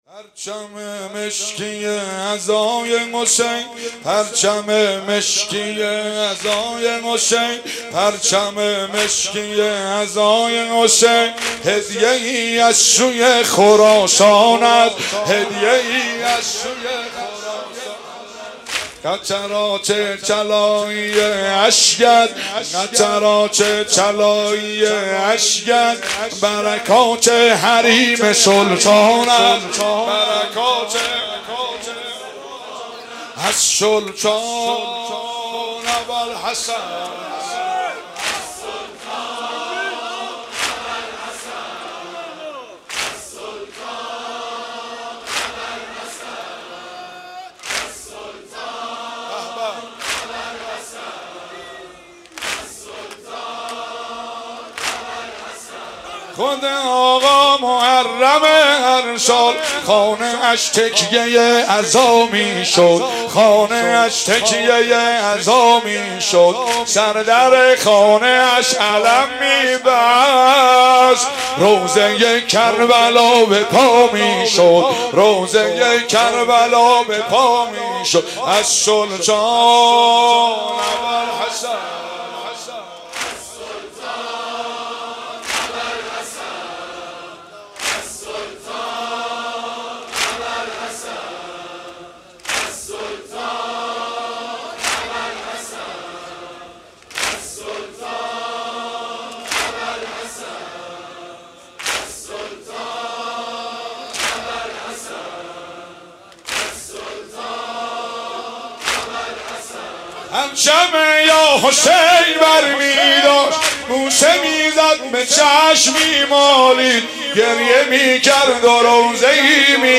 محرم 96